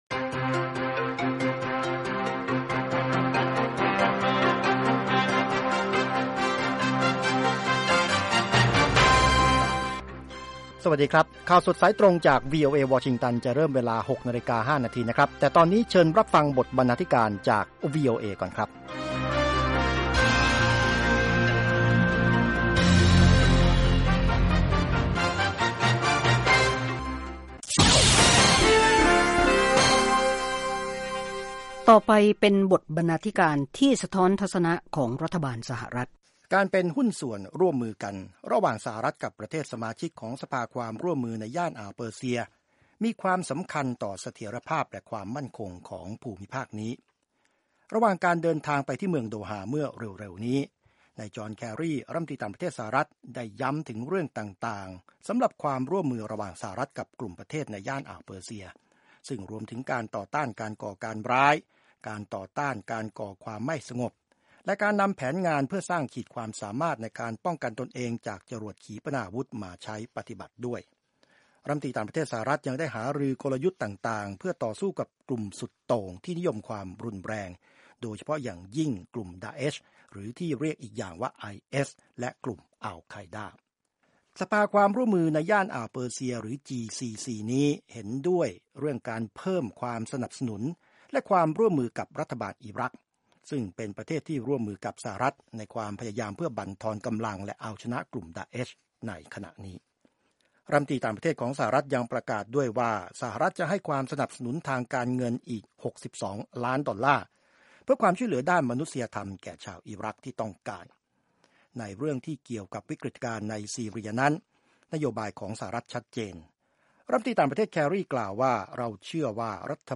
ข่าวสดสายตรงจากวีโอเอ ภาคภาษาไทย 6:00 – 6:30 น.วันพฤหัสบดี 13 ส.ค. 2558